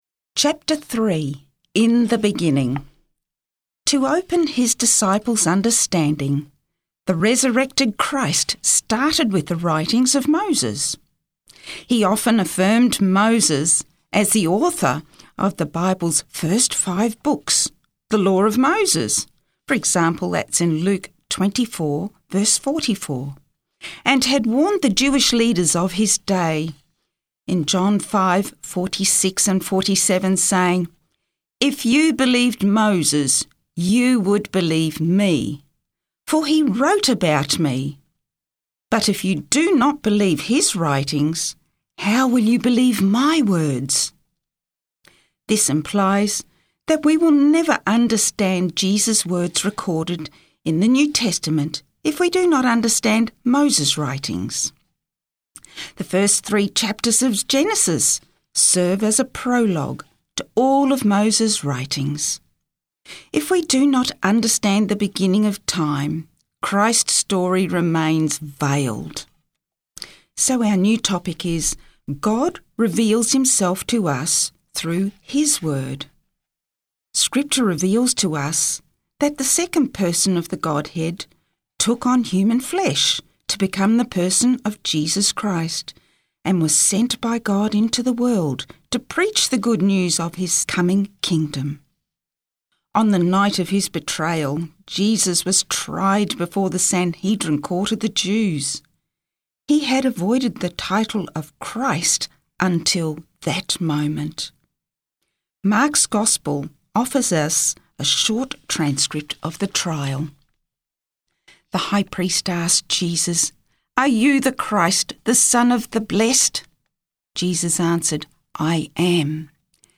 Book Reading